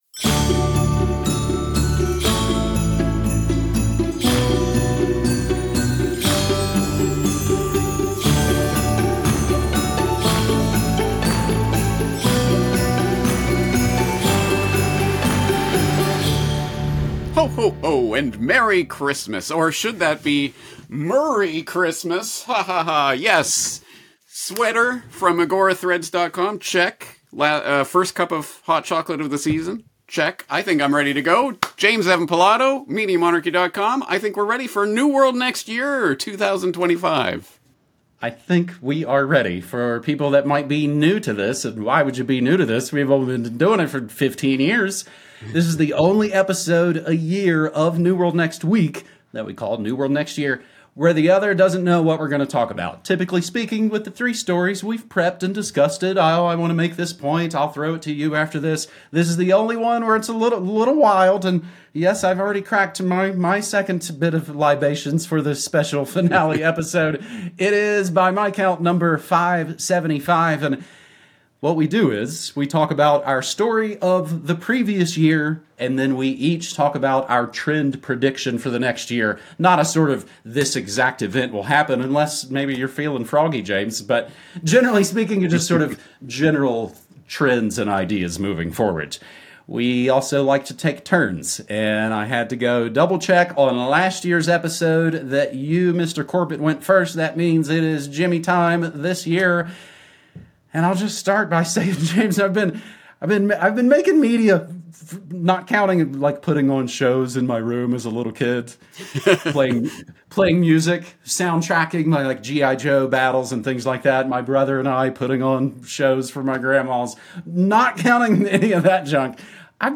Interview 1921 – New World Next Year 2025